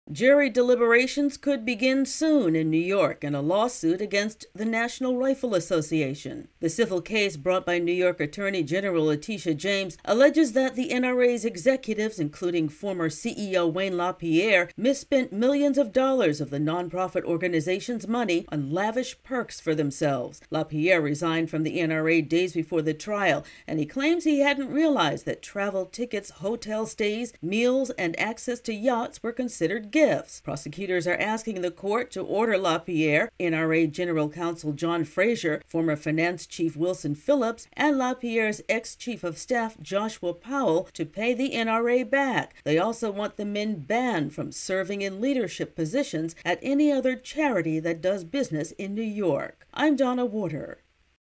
reports on a court case against former NRA officials.